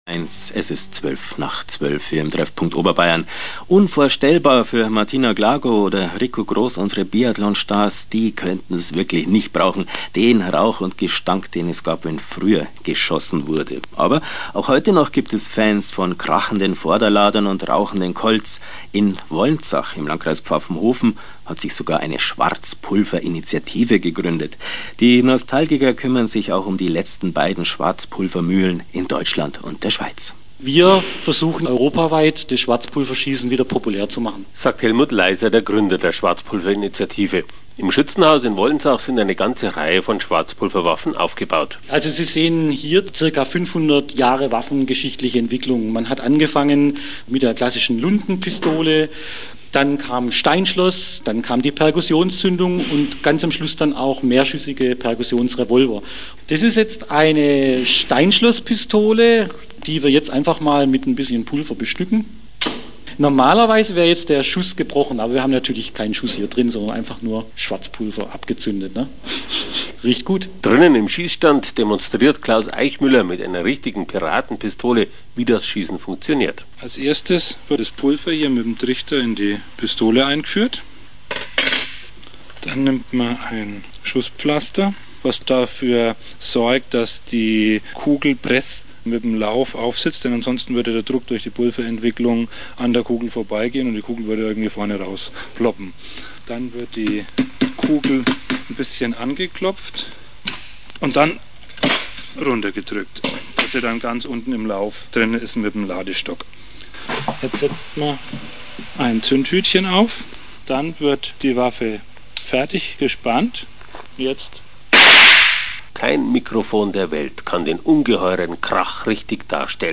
auf dem Schießstand in Wolnzach